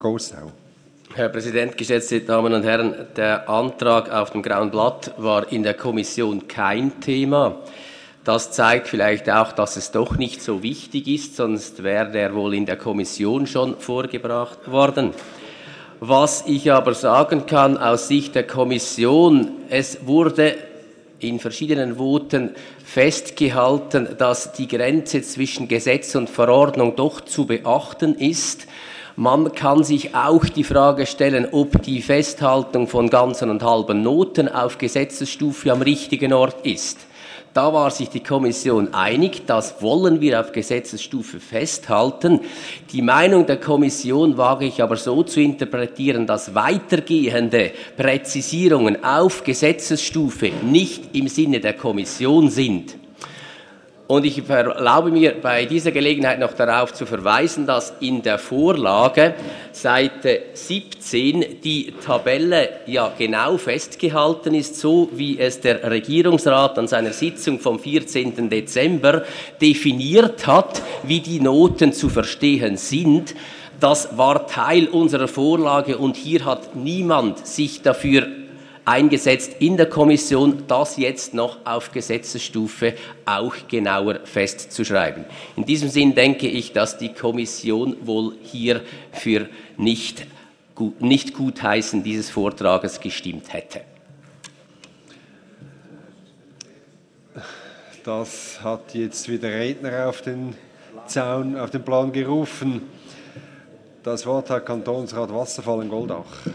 25.4.2017Wortmeldung
Session des Kantonsrates vom 24. und 25. April 2017